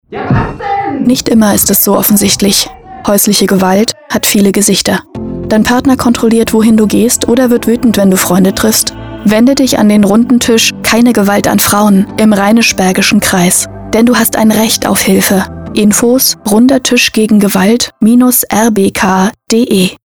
Funkspots: Sie werden im Aktionszeitraum wieder bei Radio Berg zu hören sein!